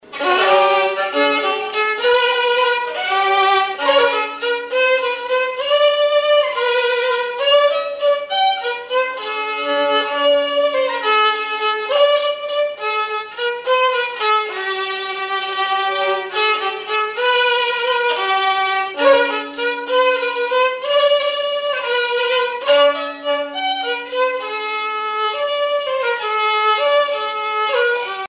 Mémoires et Patrimoines vivants - RaddO est une base de données d'archives iconographiques et sonores.
violoneux, violon
valse musette
Répertoire au violon
Pièce musicale inédite